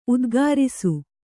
♪ udgārisu